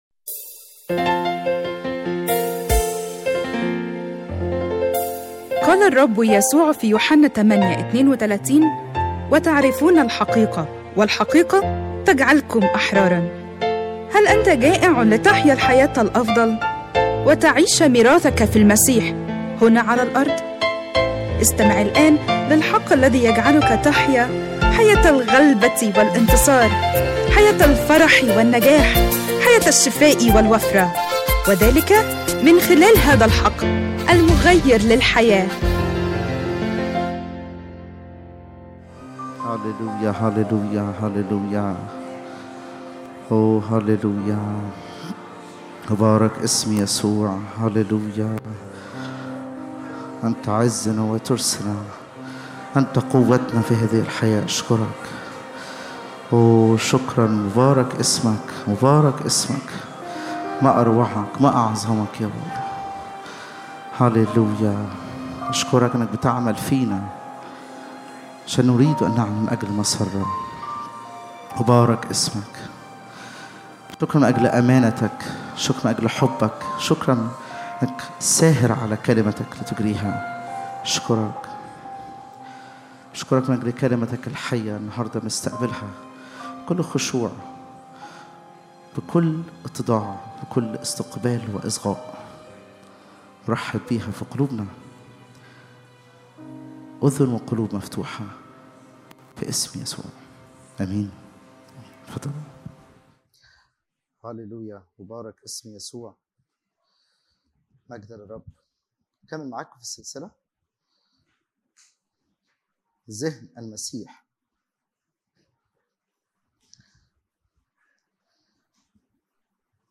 لسماع العظة على الساوند كلاود أضغط هنا